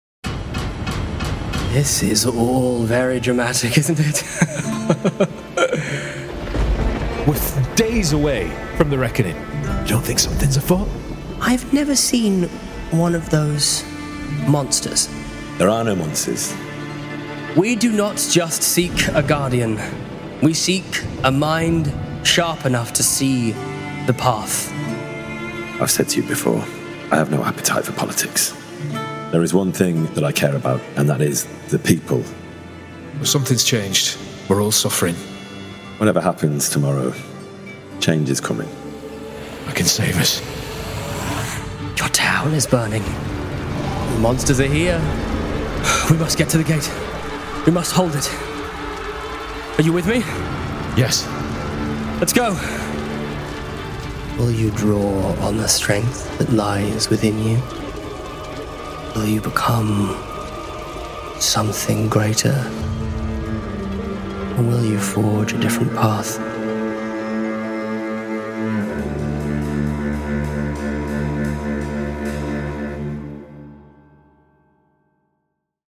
Oaths and Empires is a new dark fantasy actual play adventure featuring Kingdom Come